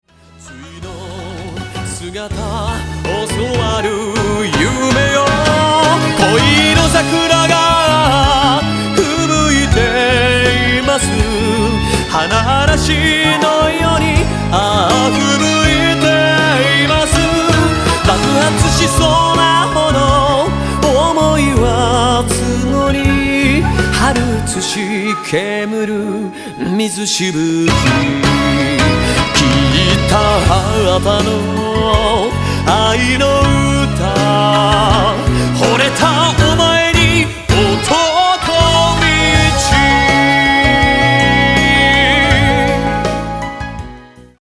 アイドルソング